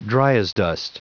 Prononciation du mot dryasdust en anglais (fichier audio)
dryasdust.wav